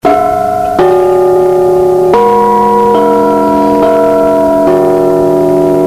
The 6 dot windbell (24") has even richer and mellower tones. The sound of this windbell is warm and soothing to the ear.